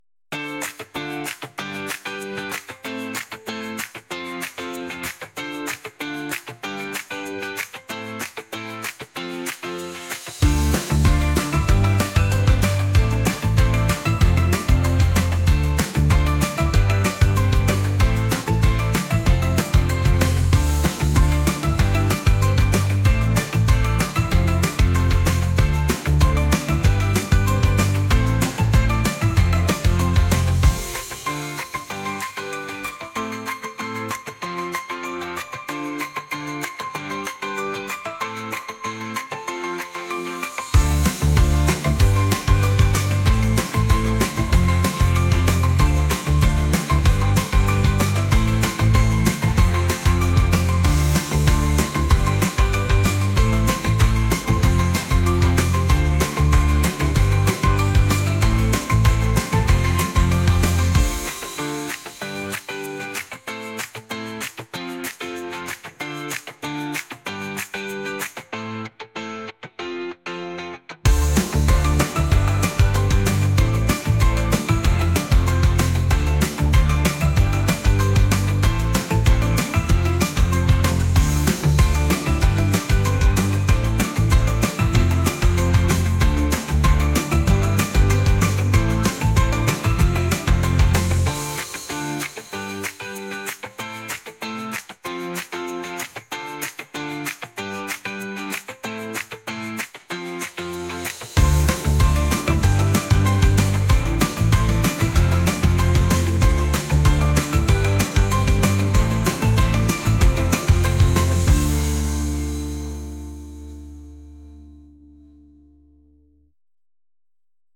pop | acoustic | lofi & chill beats